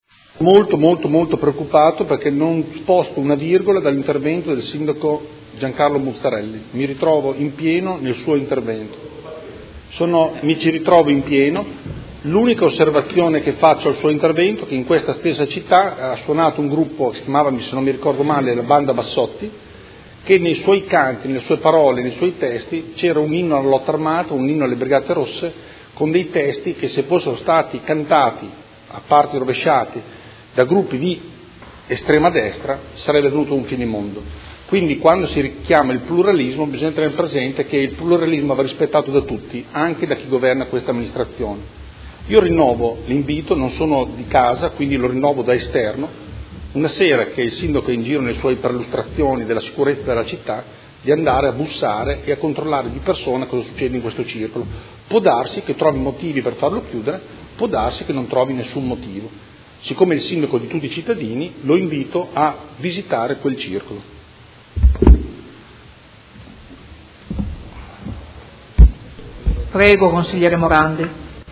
Andrea Galli — Sito Audio Consiglio Comunale
Seduta del 2/2/2017. Interrogazione del Consigliere Galli (F.I.) avente per oggetto: Circolo “Terra dei Padri”; cosa può fare l’Amministrazione per garantire il diritto di riunione ai soci e la tranquillità agli abitanti della zona?